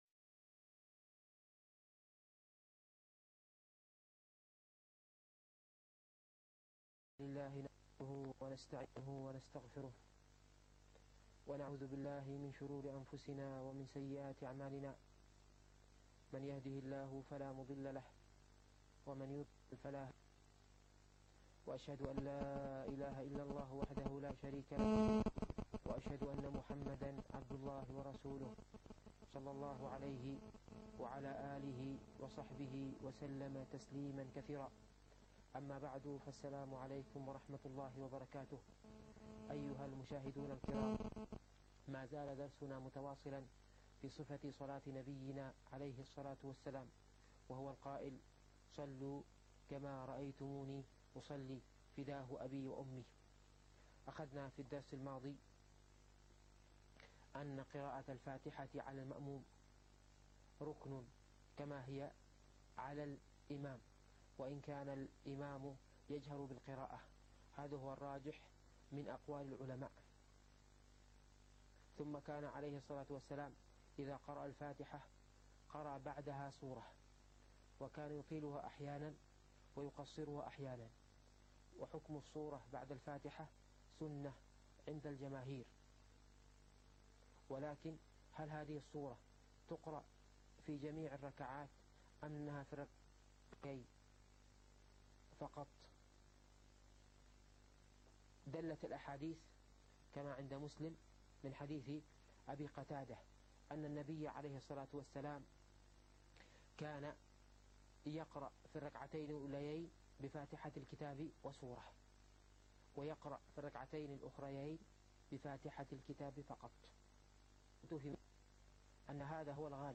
الفقه الميسر - الدرس العشرون